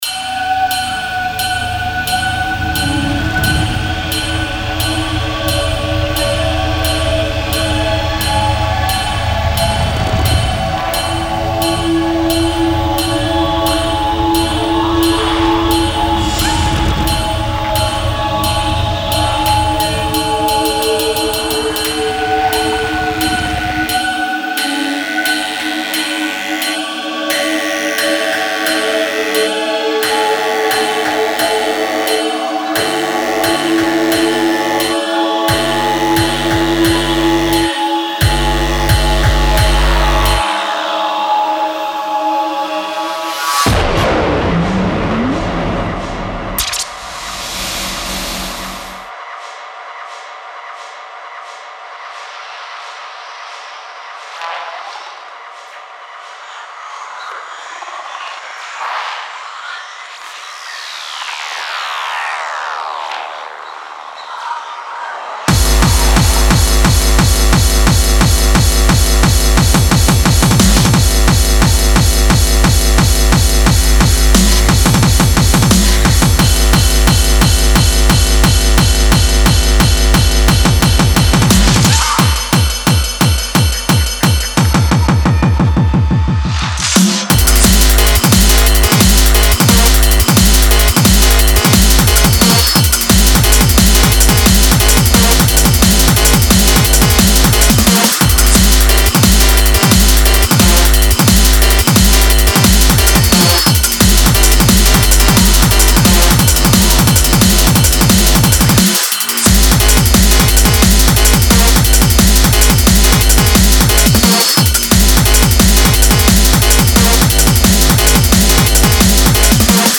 kick out the piano